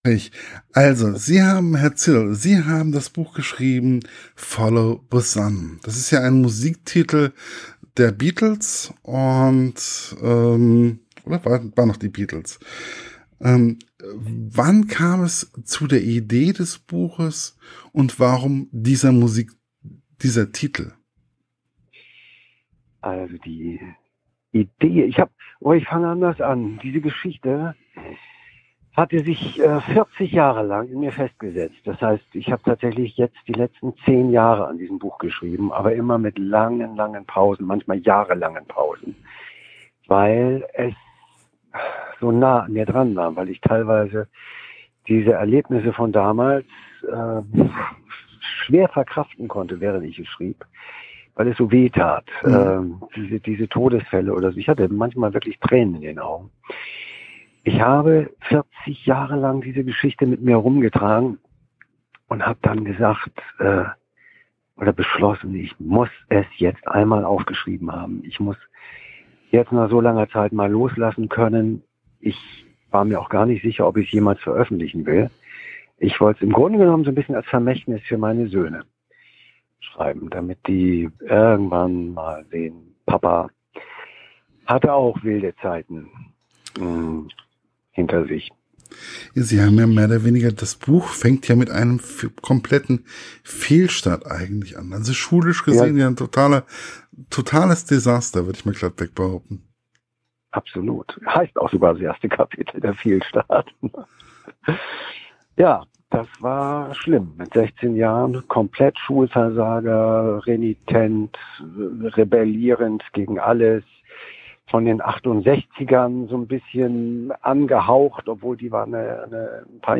[Podcast-Interview] Mit Helmut Zierl über Das Buch: Follow The Sun - LiteraturLounge